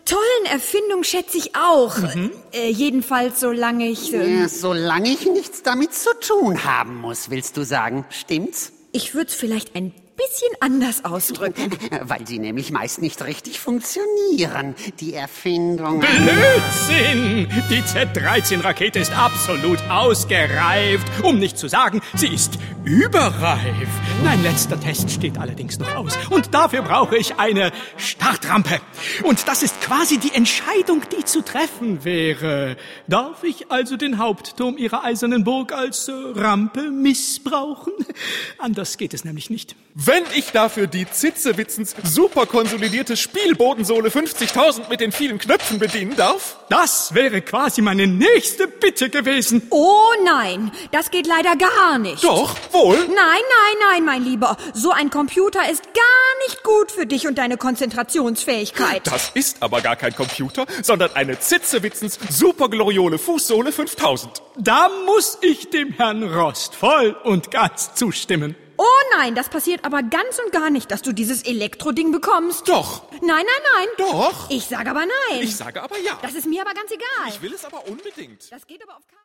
Ravensburger Ritter Rost 10 - Im Weltraum ✔ tiptoi® Hörbuch ab 3 Jahren ✔ Jetzt online herunterladen!